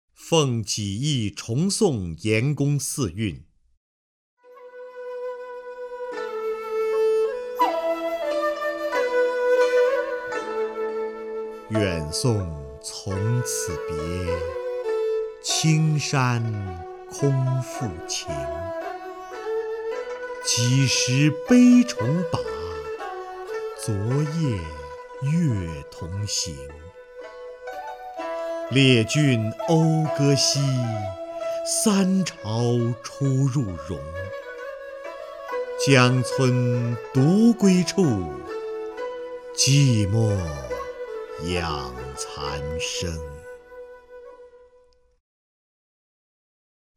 首页 视听 名家朗诵欣赏 瞿弦和
瞿弦和朗诵：《奉济驿重送严公四韵》(（唐）杜甫)